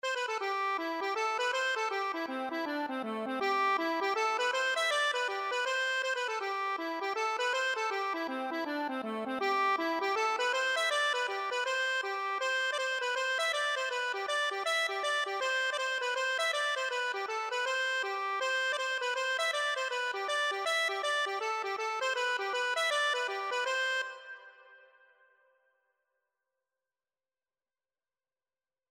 Accordion version
C major (Sounding Pitch) (View more C major Music for Accordion )
4/4 (View more 4/4 Music)
A4-E6
Instrument:
Traditional (View more Traditional Accordion Music)